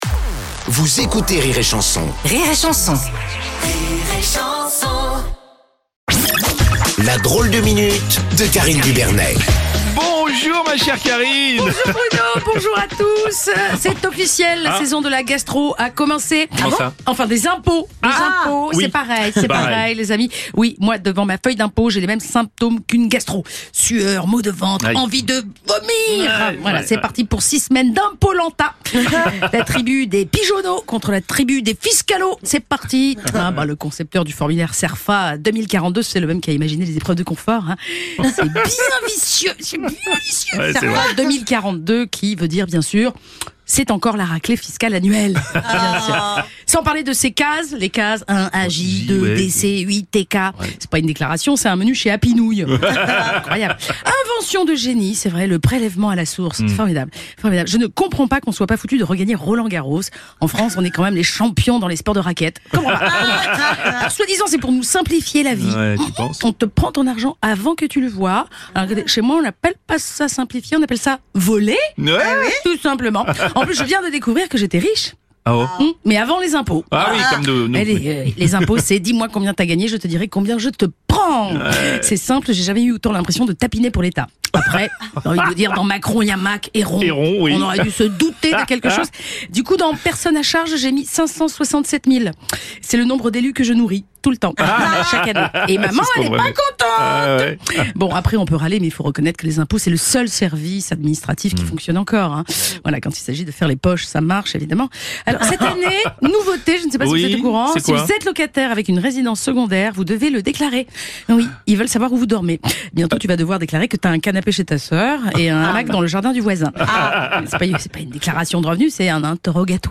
Genres: Comedy